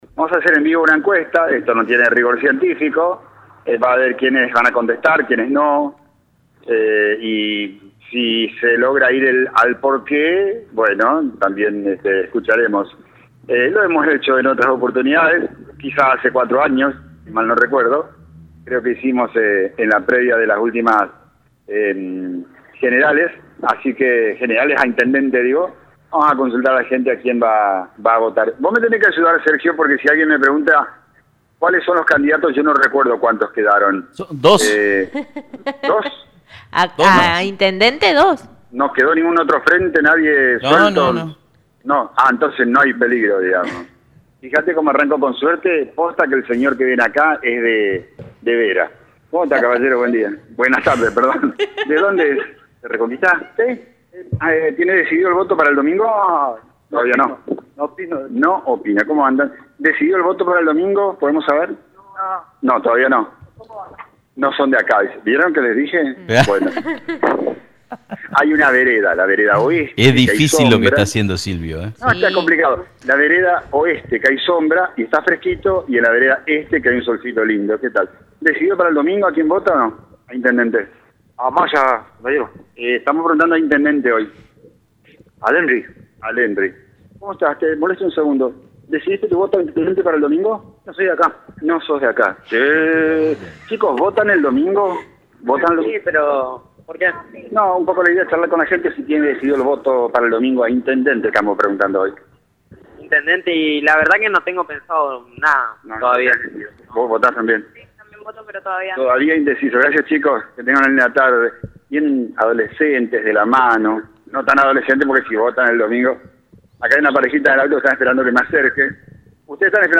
Sorpresa!!! Los increíbles números de una encuesta callejera hecha en Reconquista -Audio-